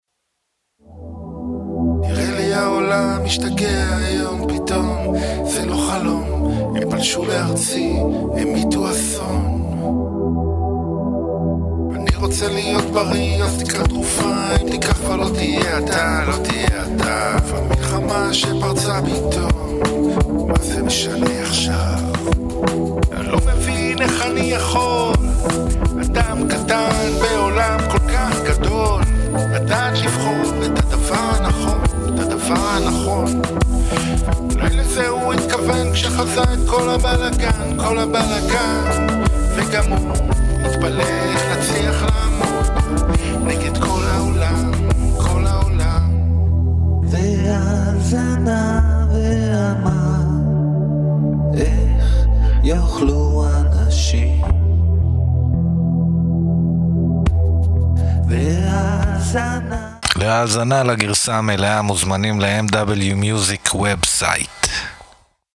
פיוטים עבריים, וסאונד מודרני.
בין מדיטטיביות לרוק ישראלי רך.